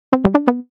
SE（話し声）
話し声。女性。てよてよ。